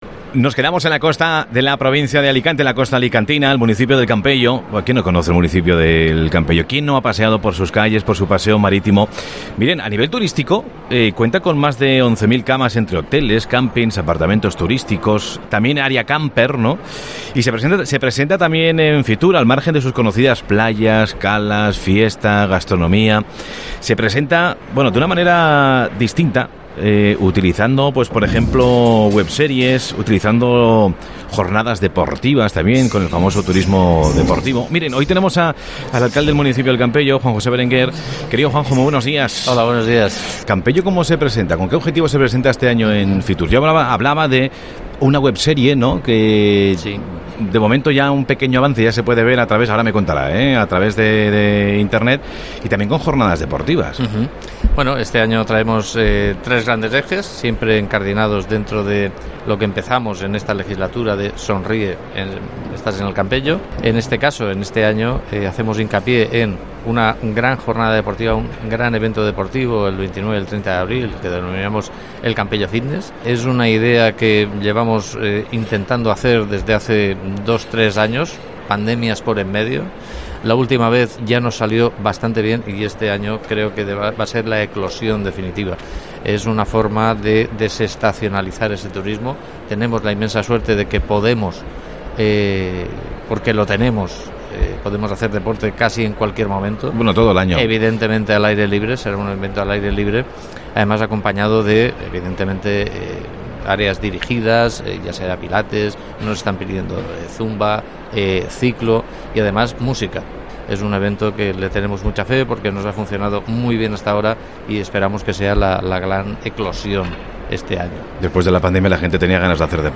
Entrevista a Juanjo Berenguer, alcalde de El Campello
AUDIO: Hablamos en Mediodía COPE desde Fitur con el primer edil del municipio costero sobre las acciones para promocionar el turismo